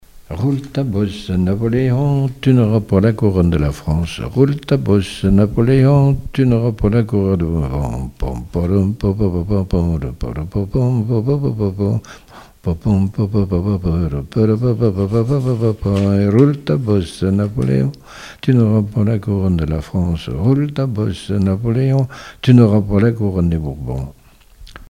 Couplets à danser
danse : branle : avant-deux
Pièce musicale inédite